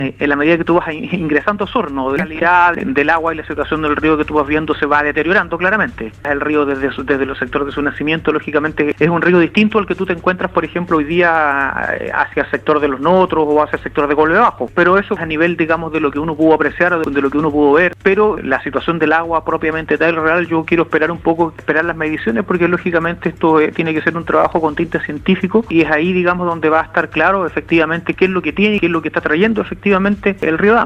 Es por eso que en conversación con Radio Sago, Bello explicó sus sensaciones en el recorrido.